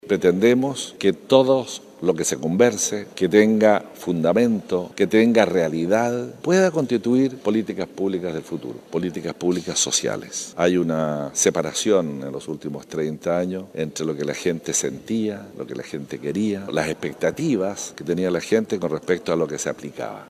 El Jefe Regional de Los Lagos, explicó que se pretende que todo los resultados de esta consulta ciudadana sirva para que a futuro pueda constituirse en políticas públicas.